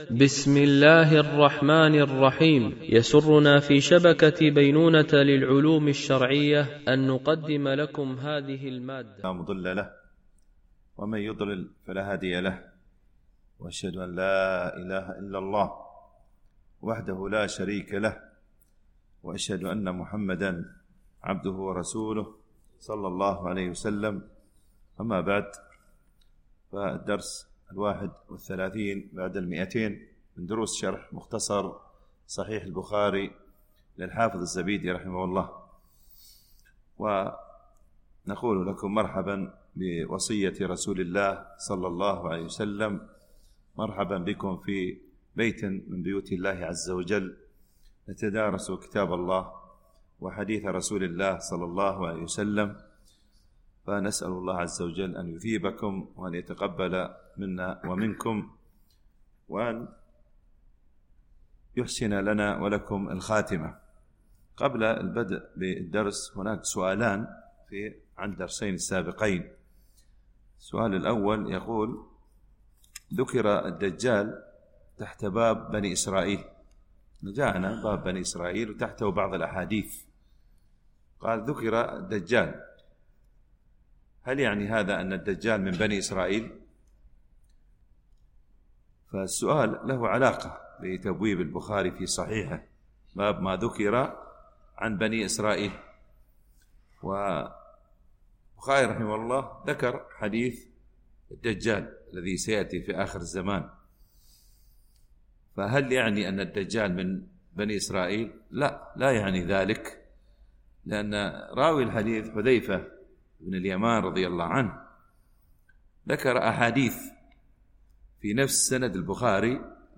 شرح مختصر صحيح البخاري ـ الدرس 231 ( كتاب أحاديث الأنبياء - الجزء الحادي عشر - الحديث 1444 - 1447 )